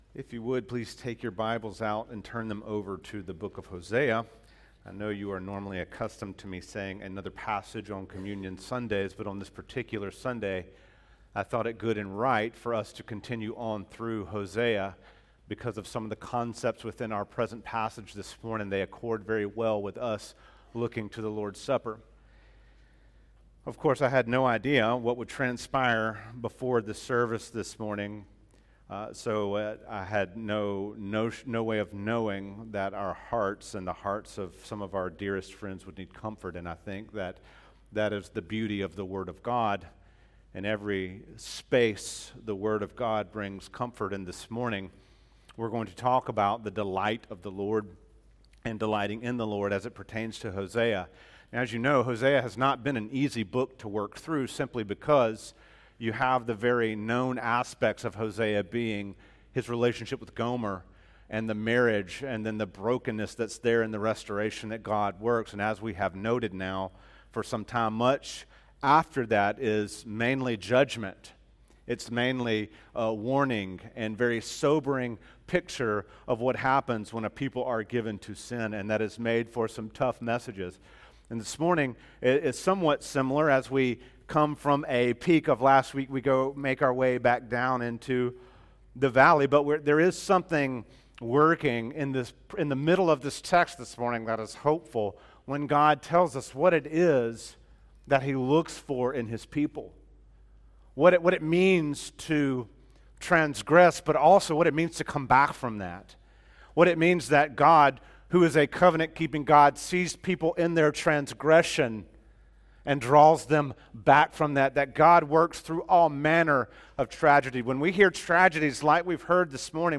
teaches from the series: Hosea, in the book of Hosea, verses 6:4 - 6:10